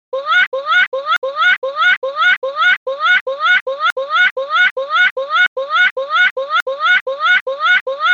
SUONI DEL PINGUINO 39741
• Categoria: Pinguino